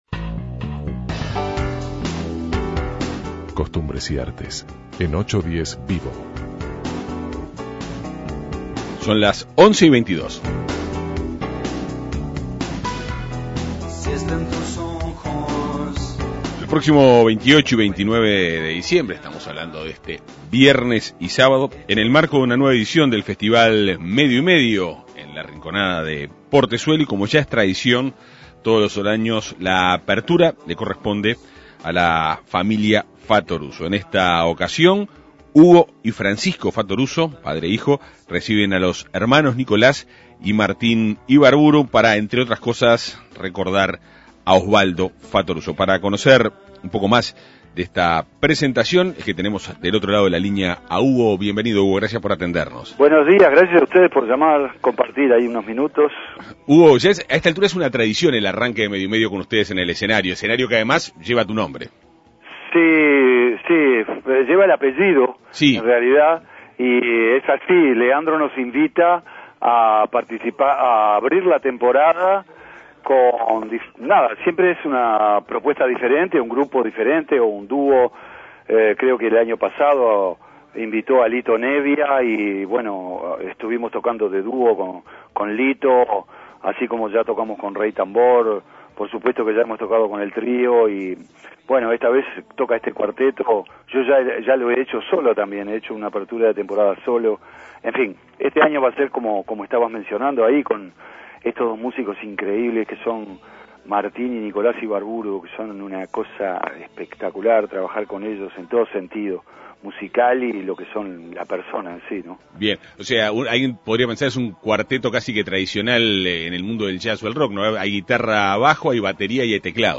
Para conocer más sobre este espectáculo, 810VIVO Avances, tendencia y actualidad conversó con Hugo Fattoruso.